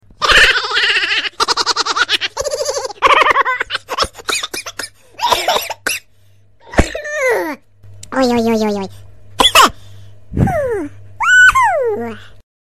Category: Laughter
laughter sound effects